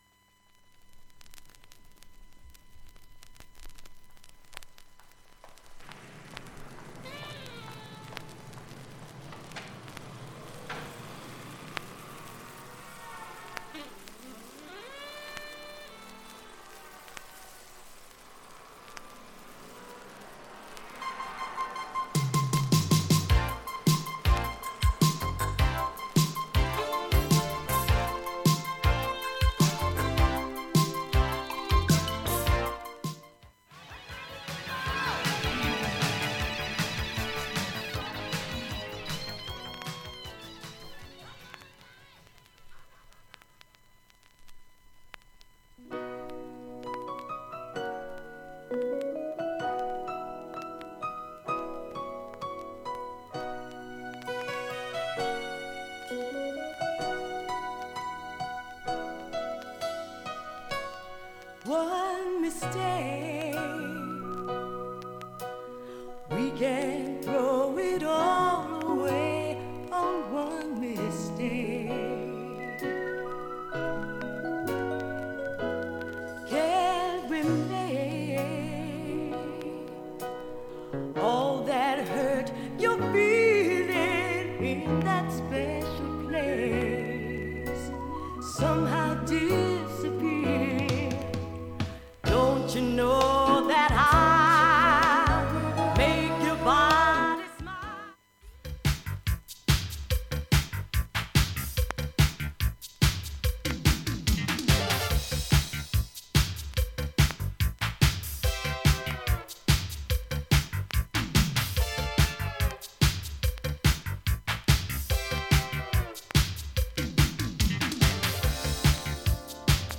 曲間、無音部にバックチリ出ます。
普通に聴けます全曲試聴済み。
A-1(B-1含む）前半は静かな部バックチリ多めです、
そのあとわずかなプツが12回出ます。
現物の試聴（上記録音時間4分半）できます。音質目安にどうぞ
ほか3回までのかすかなプツが3か所
単発のかすかなプツが3か所